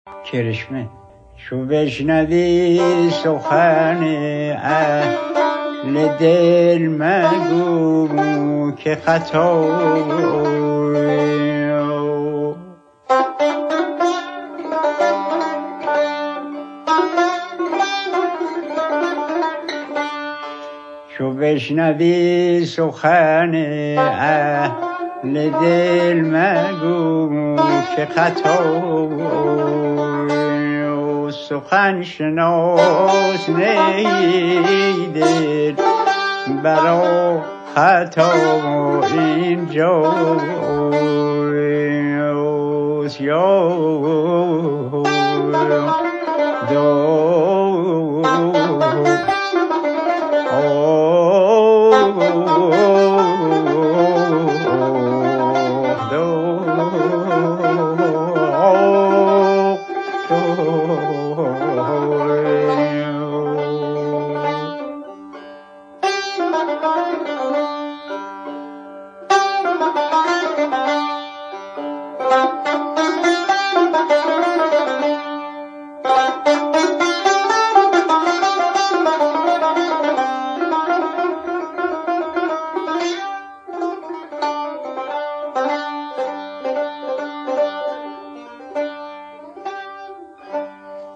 آنچه در ادامه می شنوید گوشه کرشمه از دستگاه شور به روایت استاد عبدالله دوامی است که با تار محمدرضا لطفی همراهی شده است.